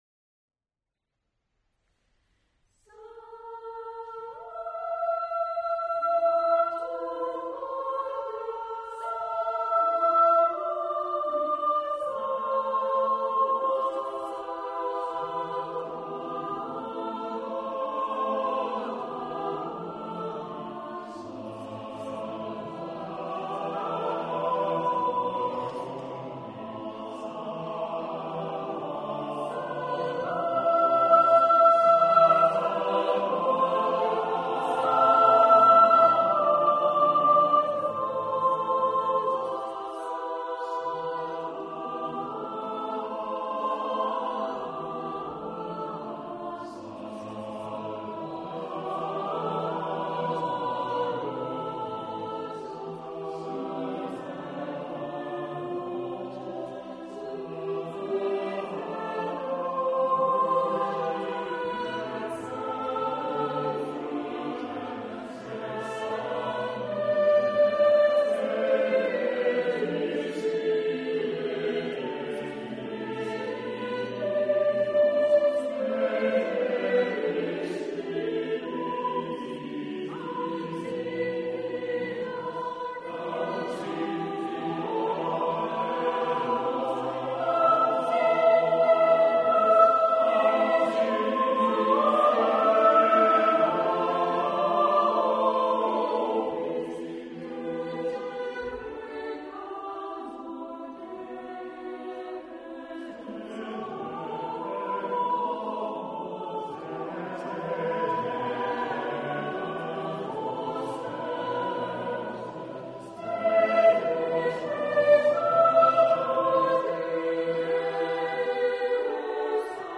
Cherwell Singers Concert March 2007
Salvator mundi I may be conventional imitative counterpoint, but it is a perfect example of its type, with aching arching lines and astonishing construction.